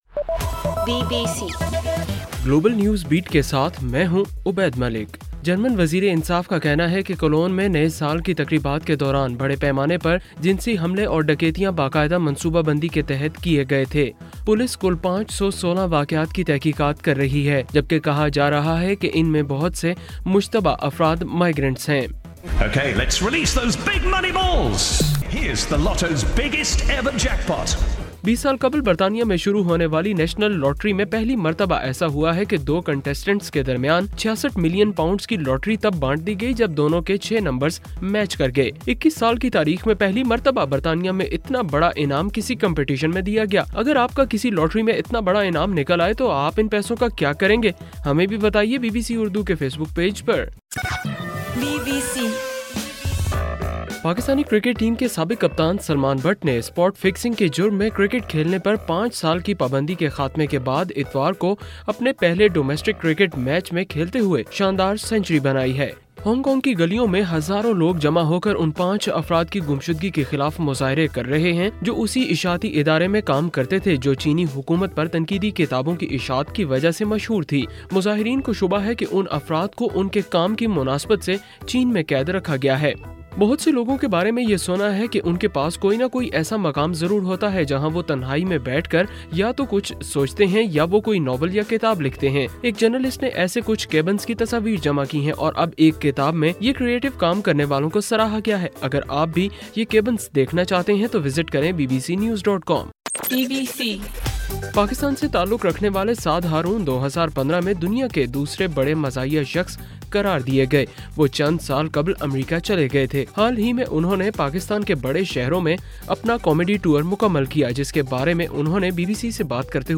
جنوری 11: صبح 1 بجے کا گلوبل نیوز بیٹ بُلیٹن